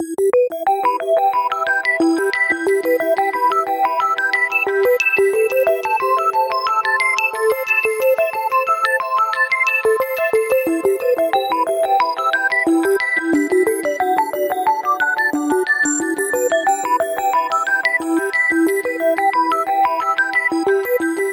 Tag: 120 bpm Disco Loops Bass Synth Loops 689.11 KB wav Key : E